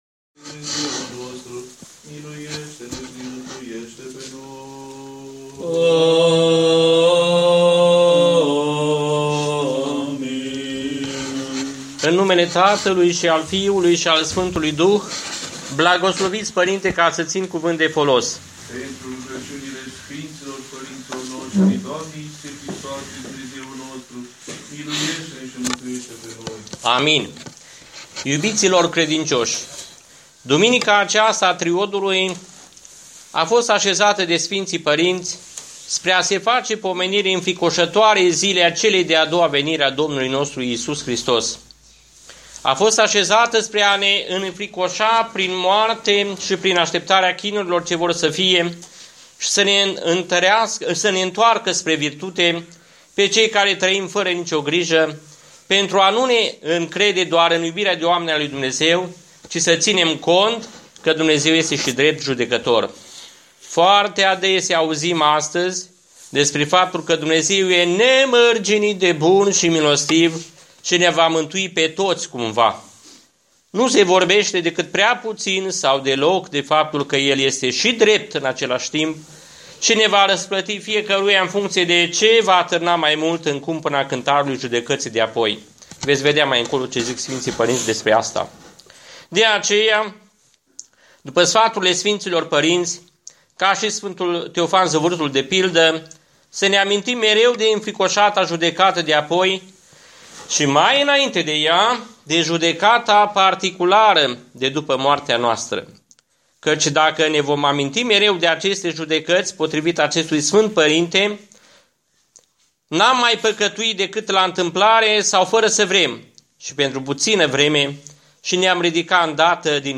Predica